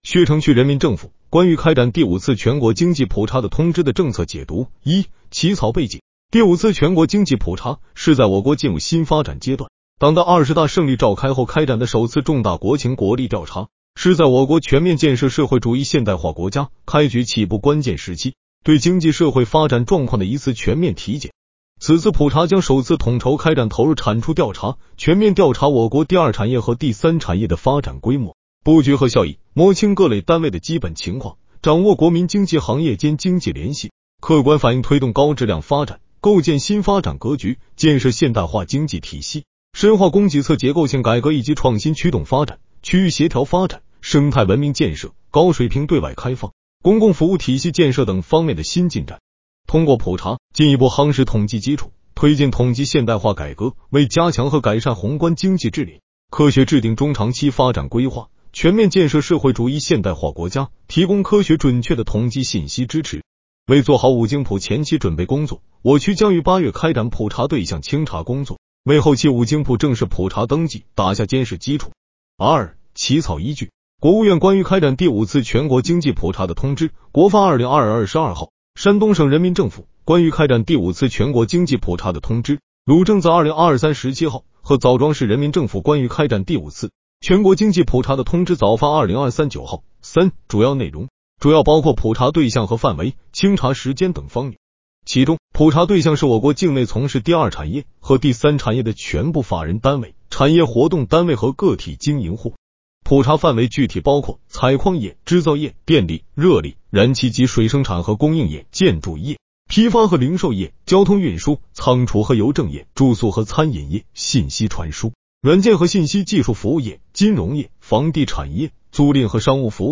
【语音解读】《薛城区人民政府关于开展第五次全国经济普查的通知》的政策解读
音频解读